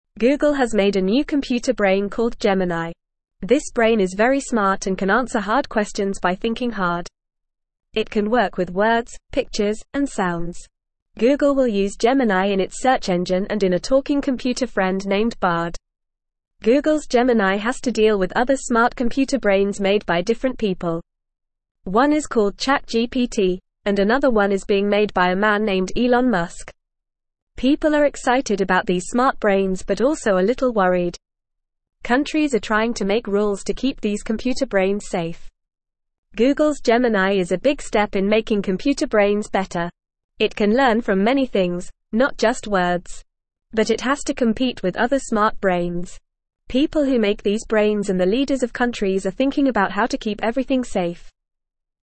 Fast
English-Newsroom-Lower-Intermediate-FAST-Reading-Googles-Smart-Computer-Friend-Gemini-Can-Answer-Questions.mp3